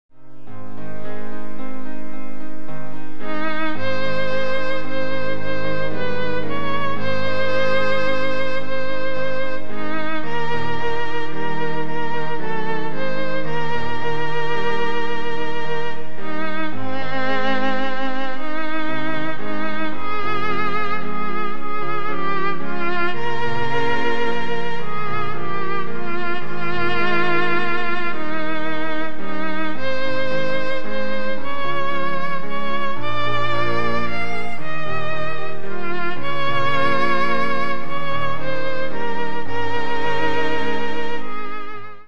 合唱 パート別・音取りＣＤ
混声合唱組曲